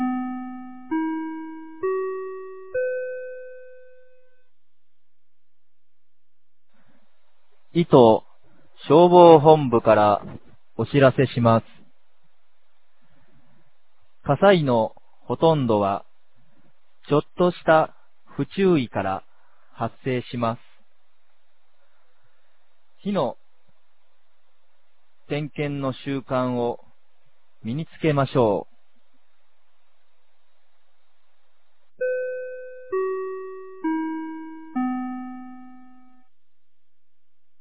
2023年05月01日 10時01分に、九度山町より全地区へ放送がありました。